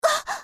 slayer_f_voc_hit_a.mp3